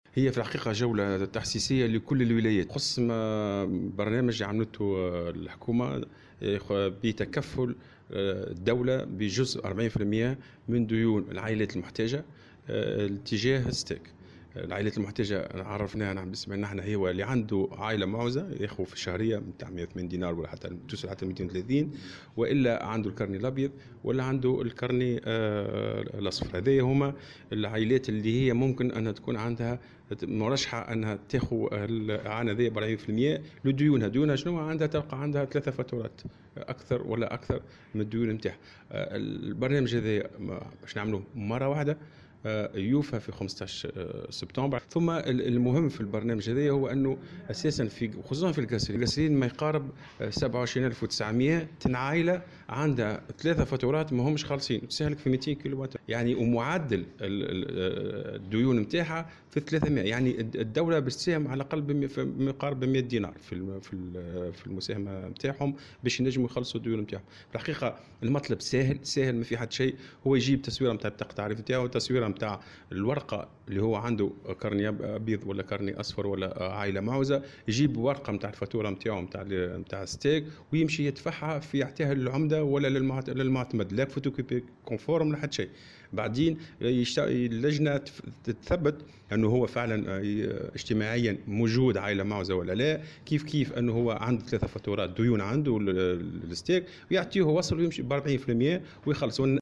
وأضاف الراجحي في تصريحه لاذاعة السيليوم أف أم أن آخر أجل لذلك حدد يوم 15 سبتمبر المقبل ،  مشيرا إلى أن الهدف من هذه المبادرة يتمثل في إعانة العائلات المعوزة  في دفع جزء من ديونهم تجاه الستاغ .